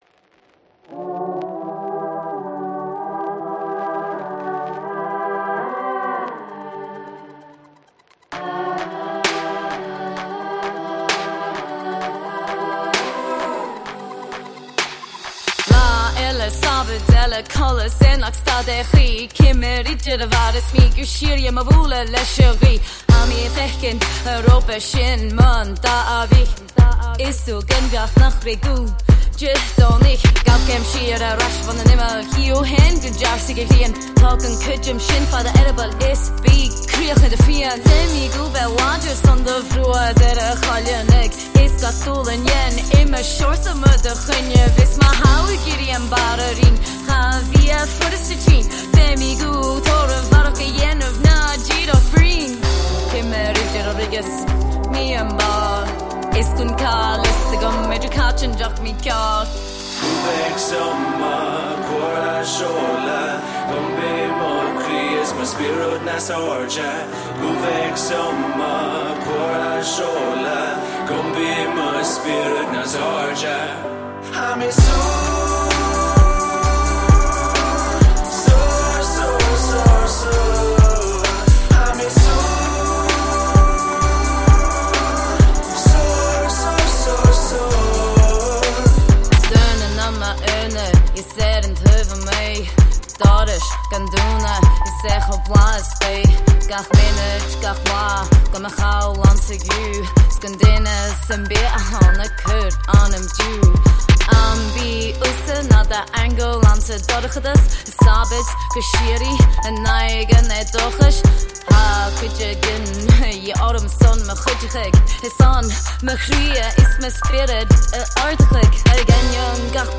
I am an Australian-born multi-arts practitioner, mostly working in Gaelic Outreach & Engagement via Contemporary Arts, such as Rap, Circus Skills, Graffiti Art & Street Theatre & Spectacle.